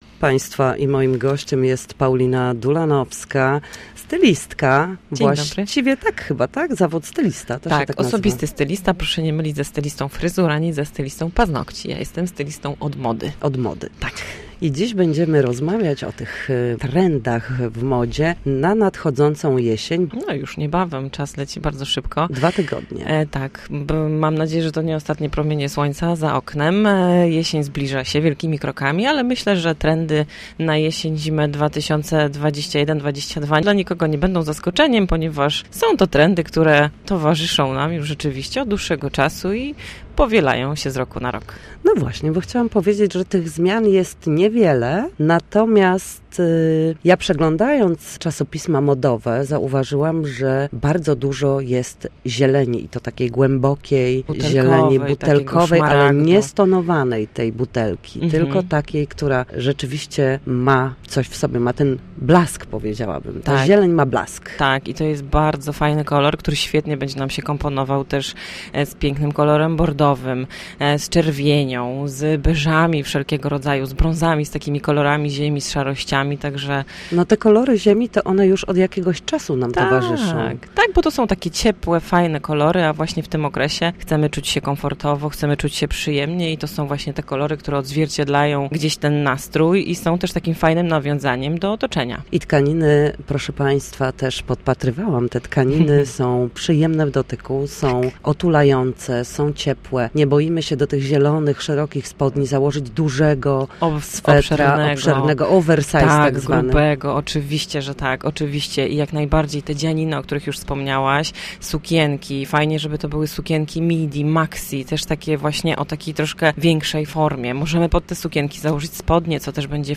Sezon na zieleń. Stylistka opowiada o jesienno-zimowych trendach